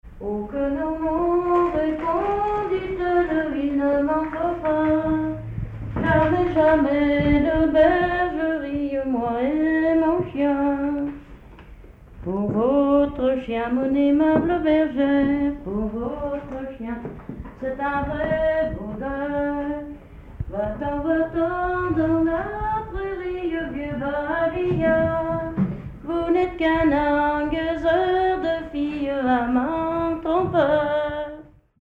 Mémoires et Patrimoines vivants - RaddO est une base de données d'archives iconographiques et sonores.
Chant de bergère
Pièce musicale inédite